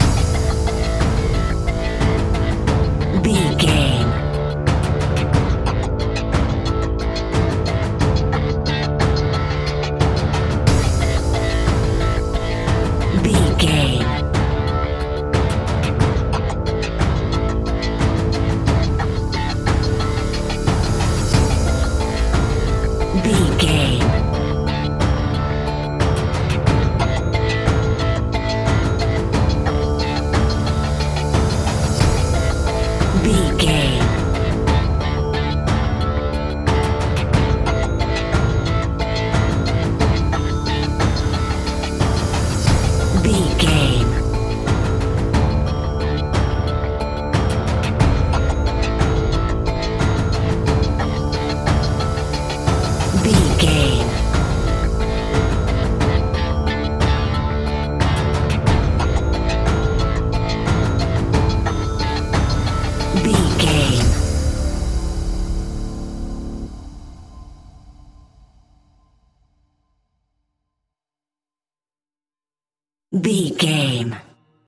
Aeolian/Minor
D
ominous
eerie
synthesiser
drums
tense
electronic music
electronic instrumentals